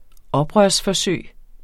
Udtale [ ˈʌbʁɶɐ̯s- ]